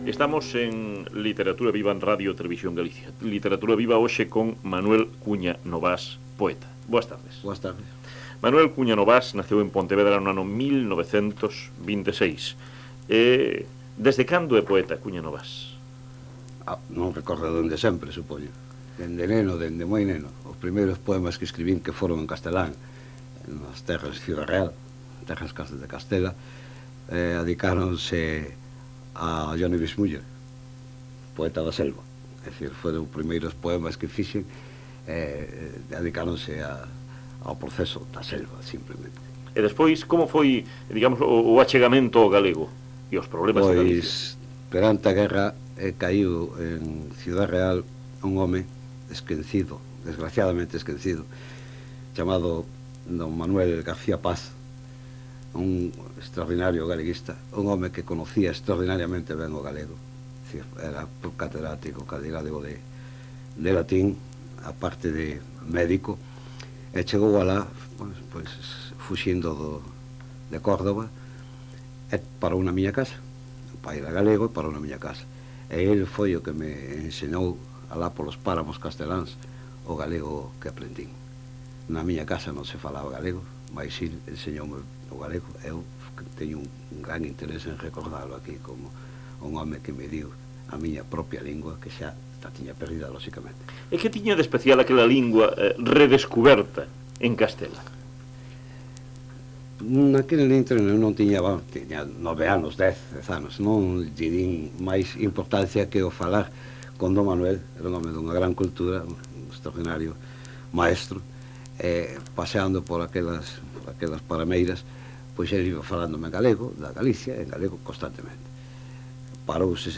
Entrevista con Manuel Cuña Novás – Poetas na súa voz – Colectivo Egeria